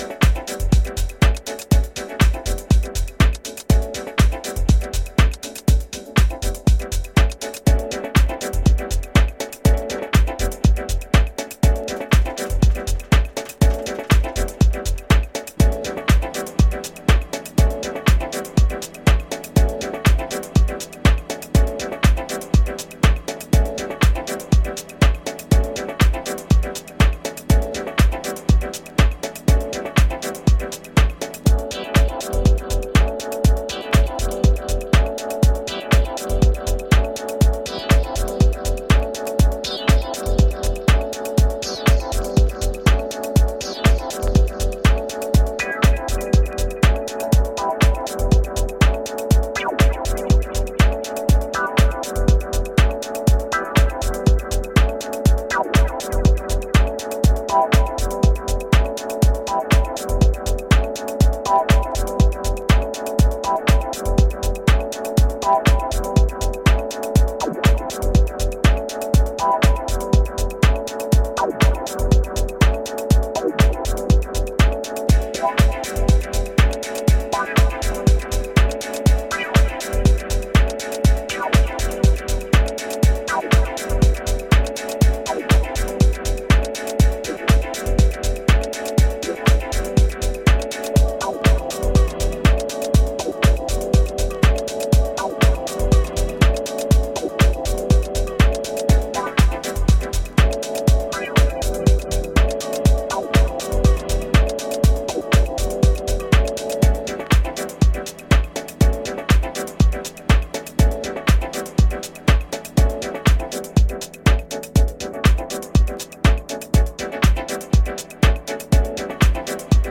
上品なシンセのダブワイズ、フロアに浸透する落ち着いたグルーヴ、染み入る余白。